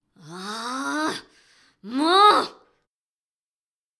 ボイス
ダウンロード 男性_「あぁぁ、もう！！」
パワフル中性中音大人